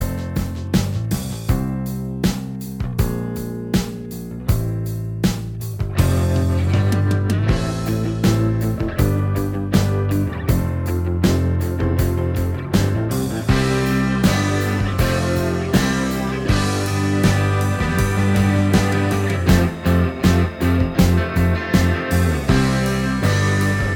Minus Acoustic Guitar Pop (1990s) 2:45 Buy £1.50